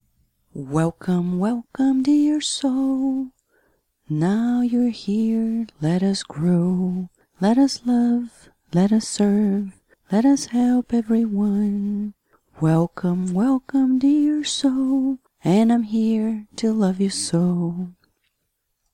Lesson_1_SB.docx LESSON PLAN "Welcome to Reincarnation" SONG Lesson 2 - March 1, 2015 Relationships are vital (click on the links on the right to see lesson plan and music mp3.)